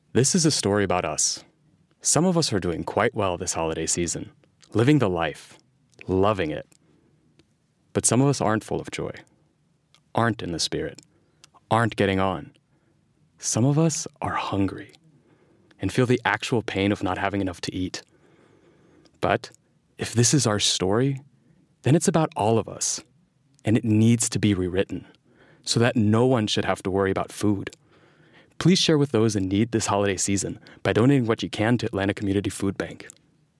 Young Adult